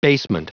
Prononciation du mot basement en anglais (fichier audio)
Prononciation du mot : basement